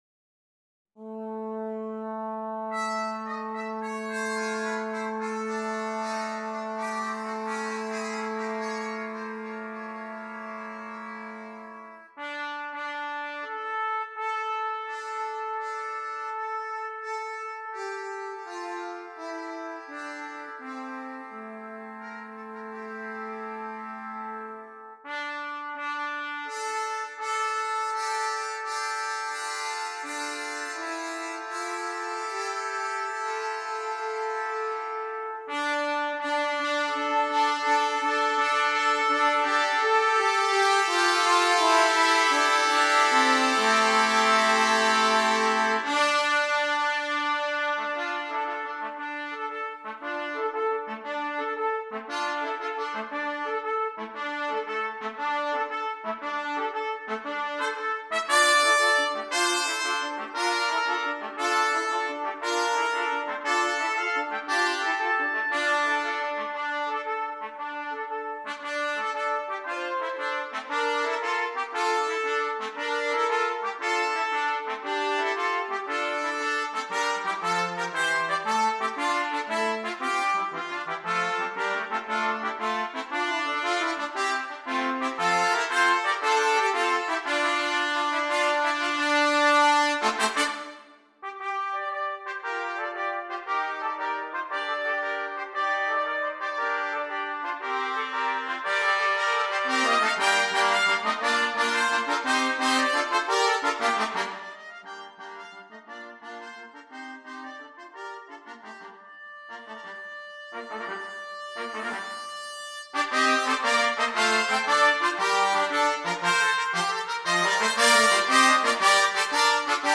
Christmas
5 Trumpets
eerie fragmentations